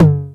TR909TOM3.wav